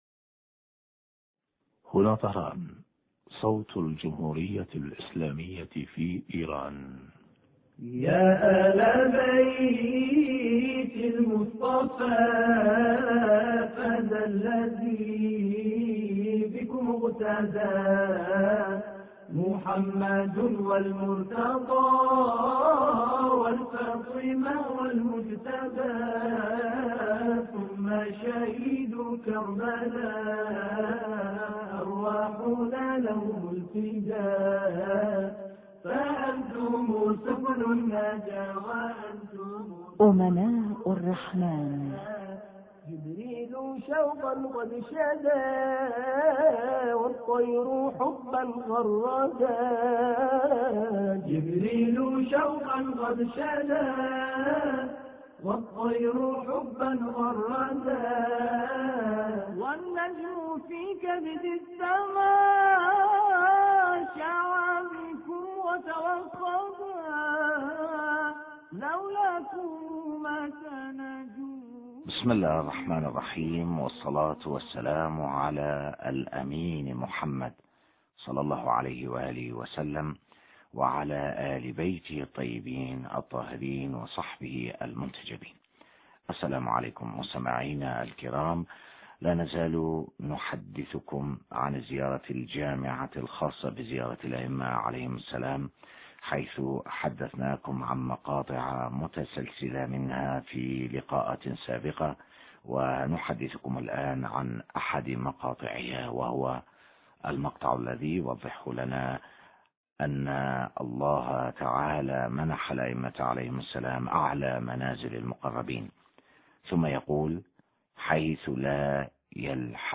شرح فقرة: حيث لا يلحق لاحق ولا يفوق فائق... حوار